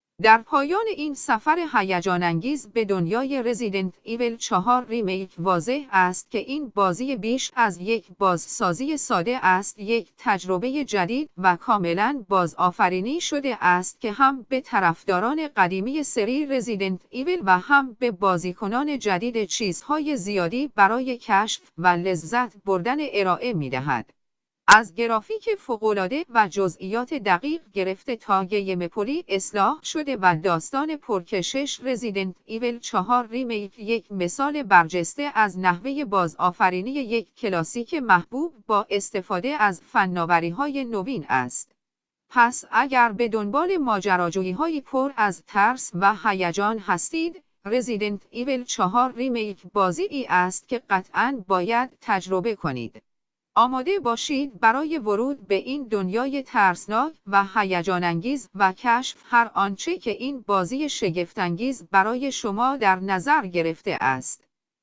صوت-خلاصه-محتوا-رزیدنت-اویل-4.ogg